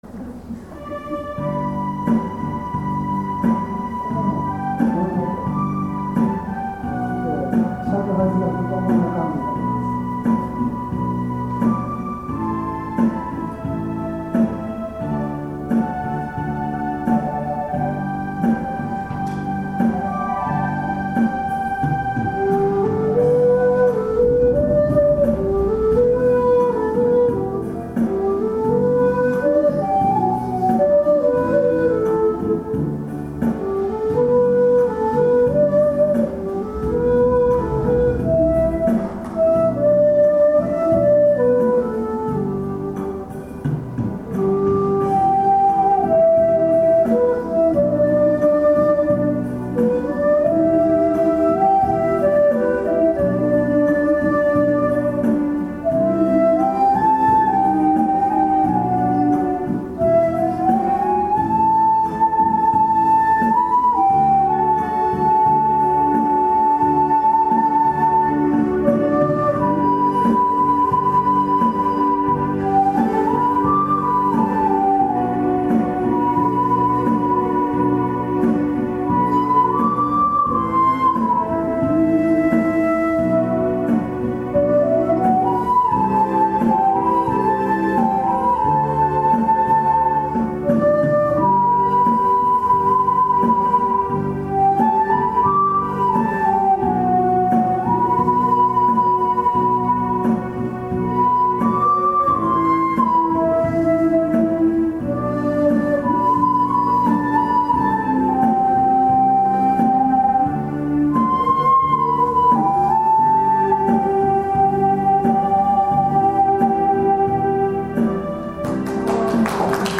さて、これがより尺八らしい一尺八寸管になりますとより人声に近くなるようです。
普通のラジカセでボリュームを上げての演奏です。
さて演奏がはじまると聴いている人が思わず尺八の声につられて口ずさんでくれました。